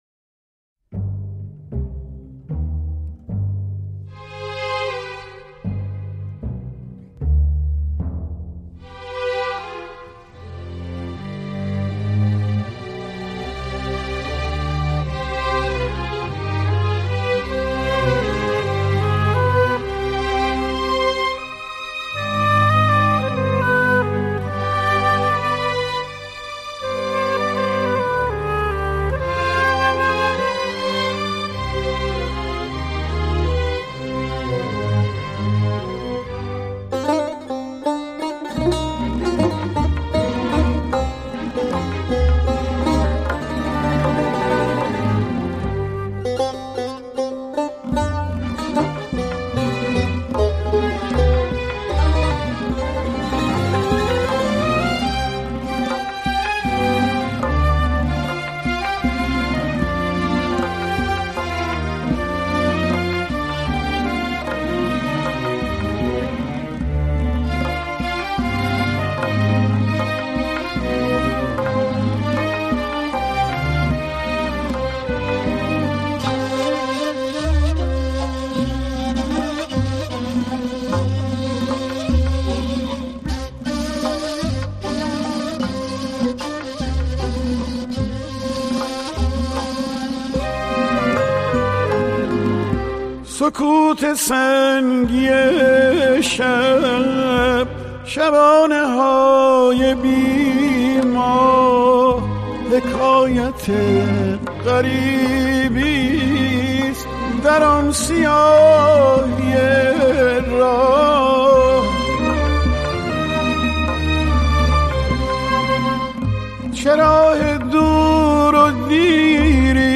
نوازنده نی